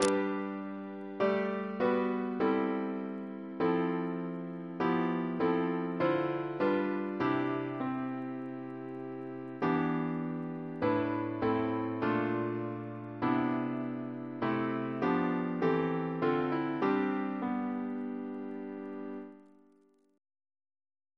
Double chant in G Composer: David Hurd (b.1950) Reference psalters: ACP: 241